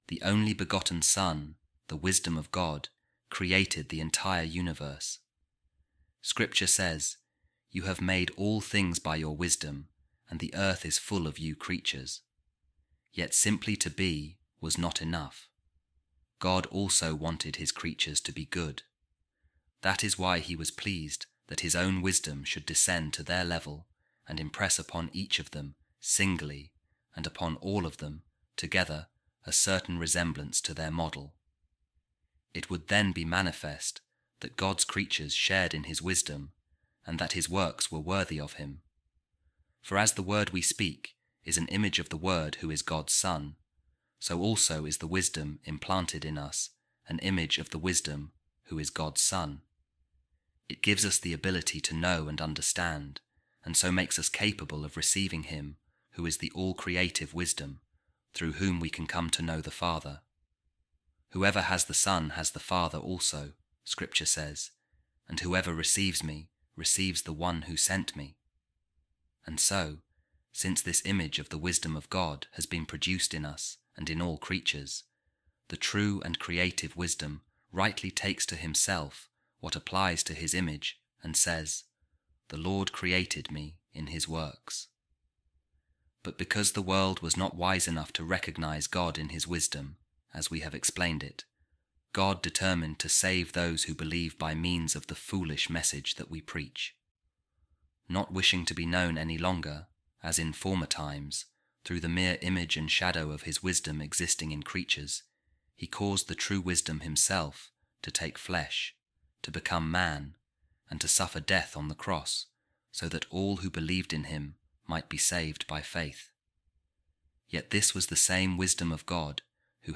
A Reading From The Discourses Against The Arians By Saint Athanasius | The Knowledge Of The Father Through Creative Wisdom Made Flesh